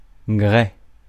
Prononciation
Synonymes séricine Prononciation France: IPA: /ɡʁɛ/ Le mot recherché trouvé avec ces langues de source: français Traduction 1.